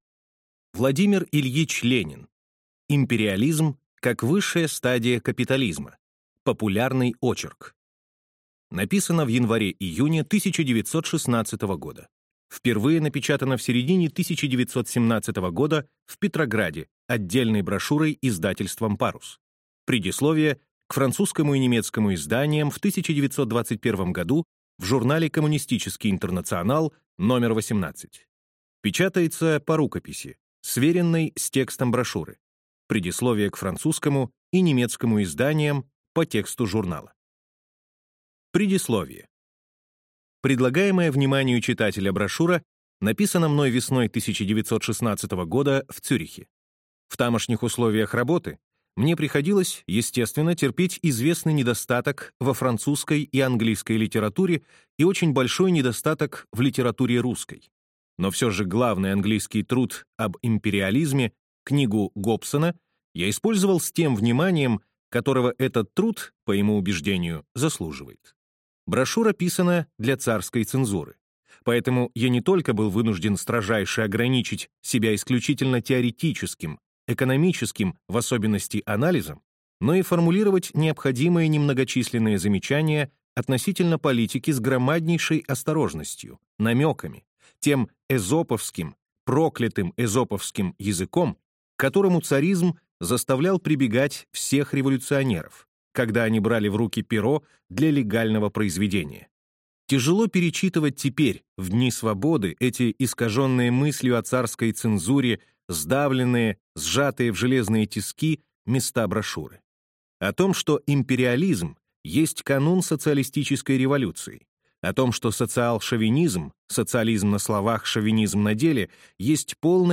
Аудиокнига Империализм, как высшая стадия капитализма | Библиотека аудиокниг